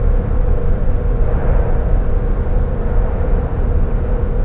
engine_room.wav